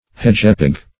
Hedgepig \Hedge"pig`\, n. A young hedgehog.